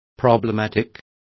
Complete with pronunciation of the translation of problematic.